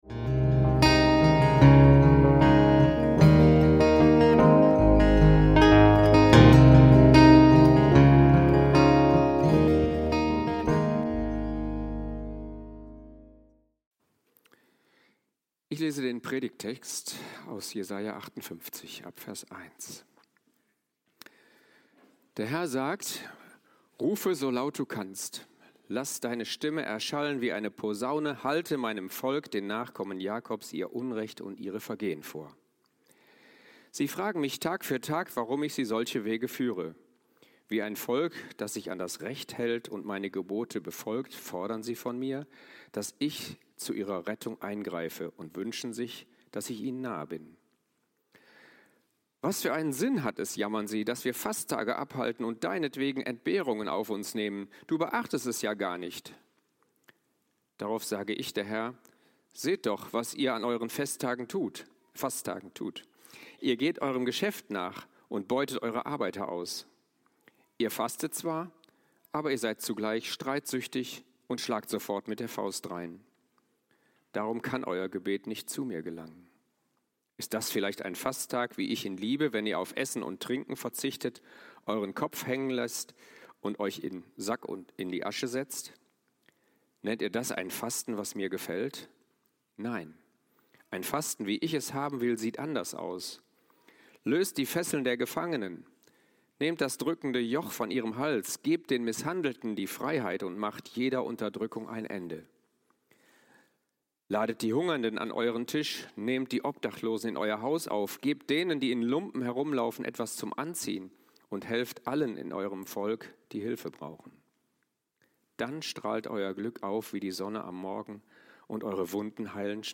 Heilen durch teilen - Predigt vom 05.10.2025 (Erntedank)